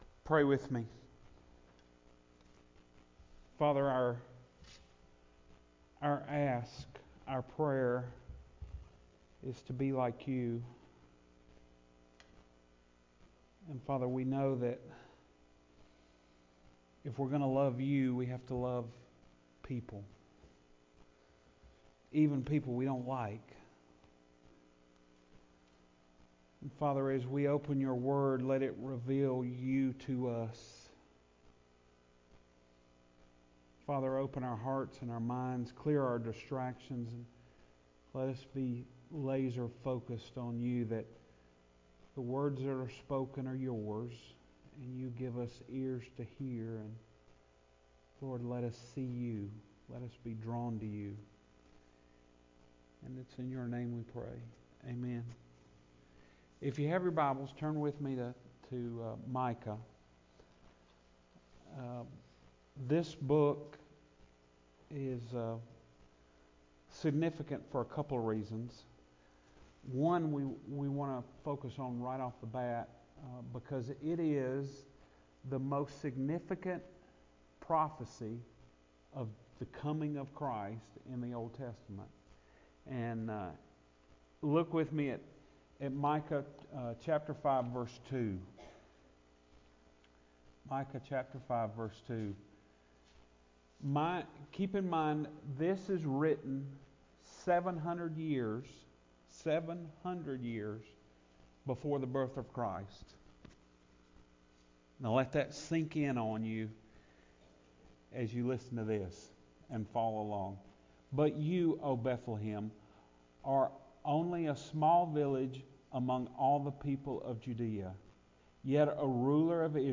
Minor Prophets - Major Lessons Sunday Morning Sermon